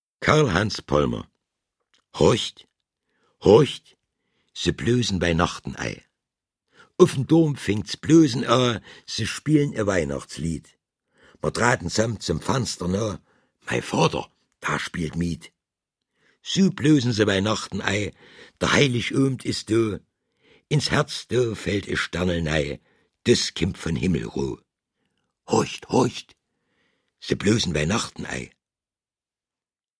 Deutsch - Mundart